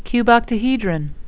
(cube-oct-a-he-dron)